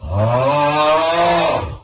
snore-1.ogg